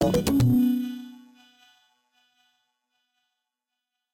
sfx_transition-06.ogg